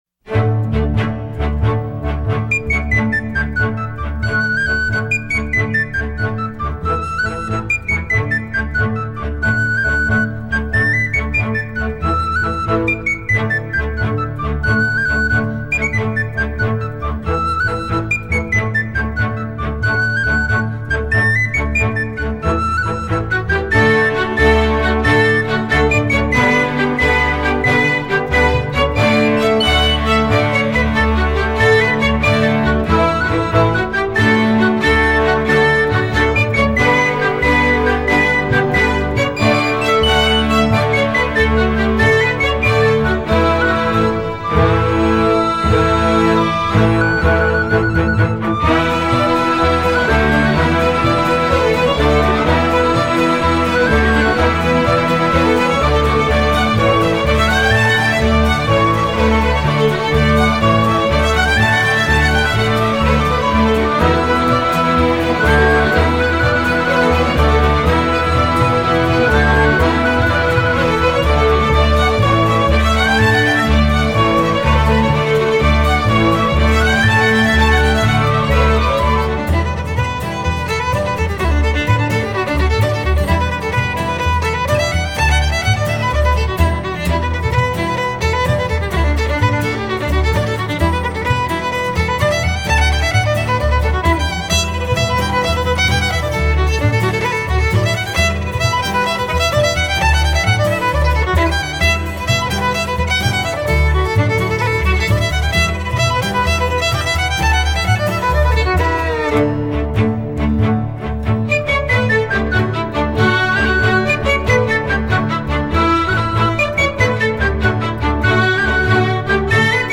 La famosissima marcetta che accompagna l’incedere e la carica del 7° Cavalleria guidato dal “Generale” Custer è così famosa che anche chi non ne conosce neppure il titolo, certamente ne conosce la melodia.
U.S._Army_Band_-_Garryowen.mp3